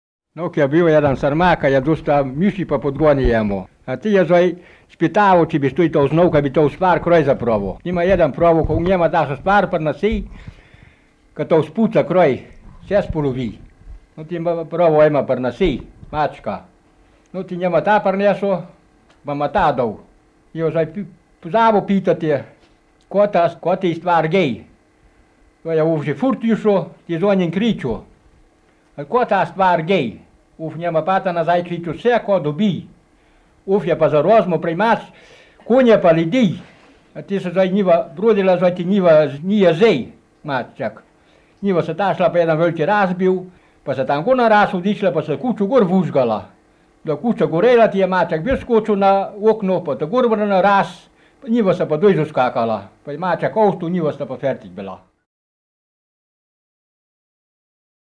V knjigi so zbrane porabske pravljice in povedke, ki jih je leta 1970 posnel Milko Matičetov na magnetofonske trakove.
Dodana je zgoščenka s tonskimi posnetki trinajstih pravljic in povedk v obeh različicah porabskega narečja (števanovskem in gornjeseniškem).